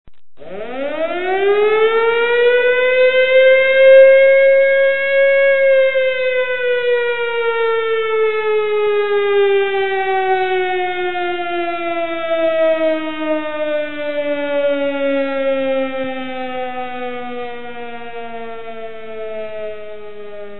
Разные звуки [11]